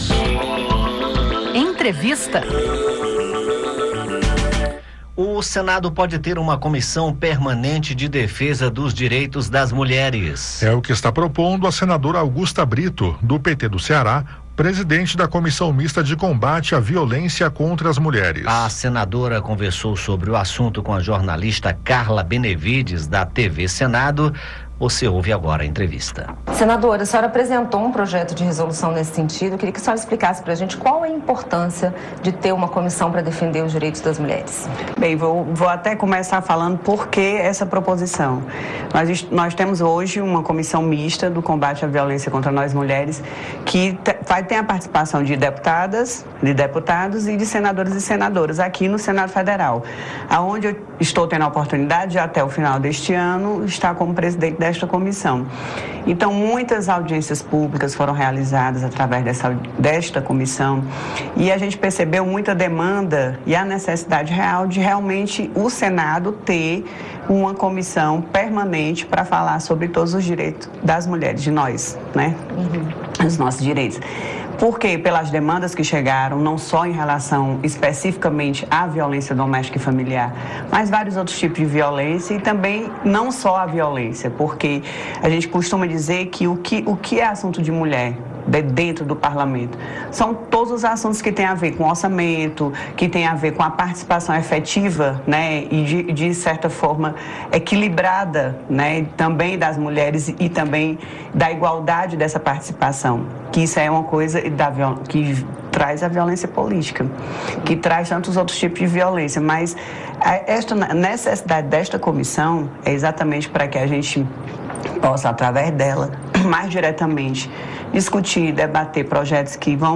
Ouça a entrevista com Augusta Brito.